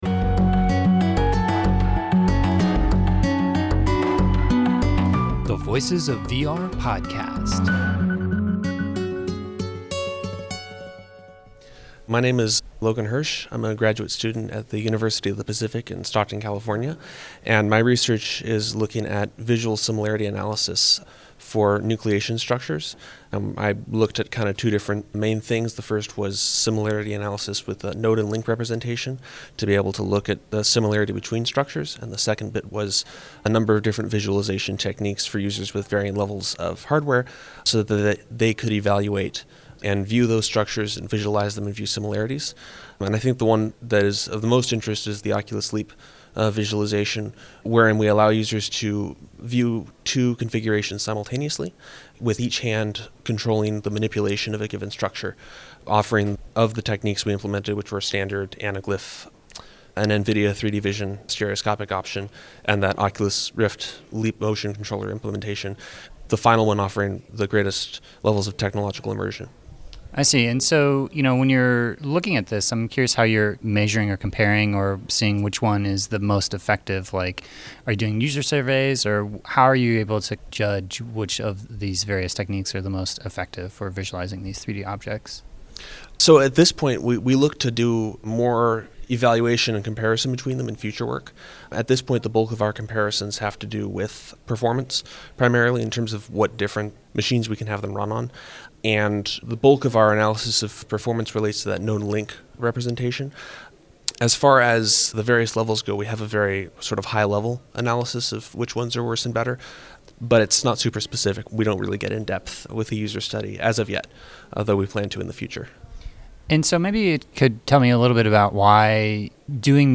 Theme music: “Fatality” by Tigoolio